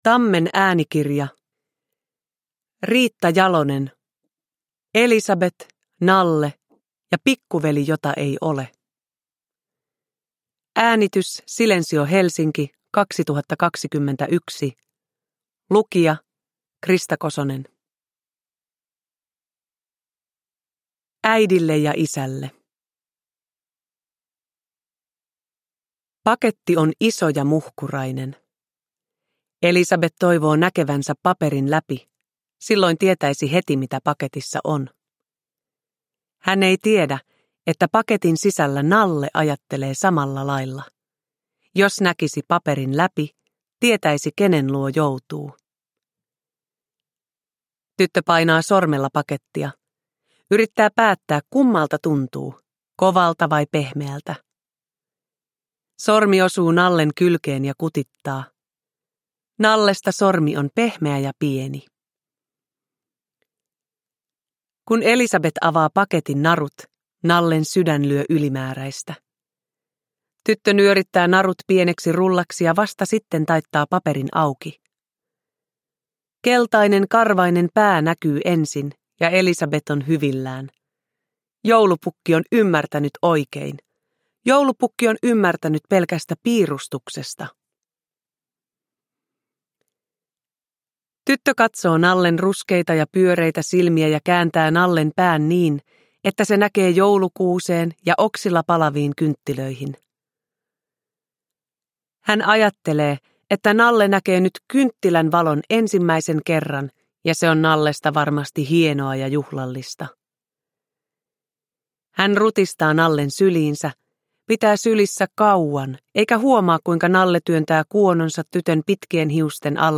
Uppläsare: Krista Kosonen